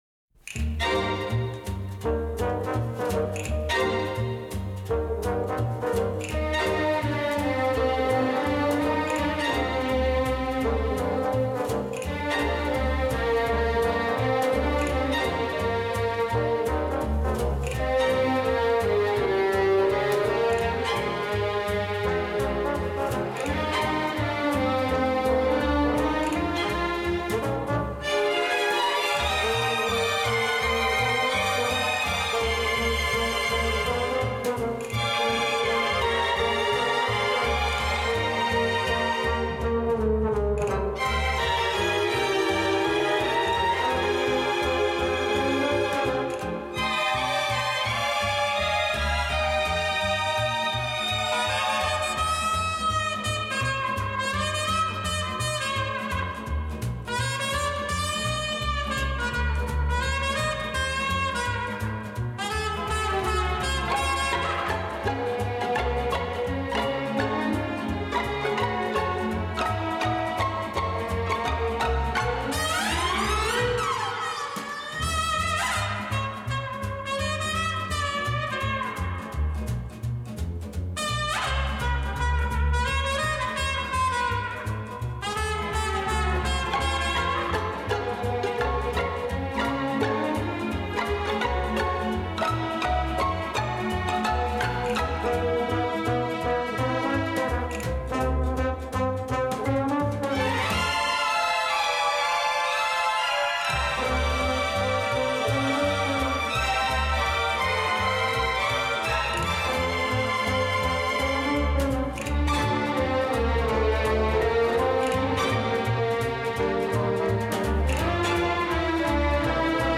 Genre:Easy Lising